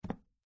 较硬的地上脚步声－左声道－YS070525.mp3
通用动作/01人物/01移动状态/01硬地面/较硬的地上脚步声－左声道－YS070525.mp3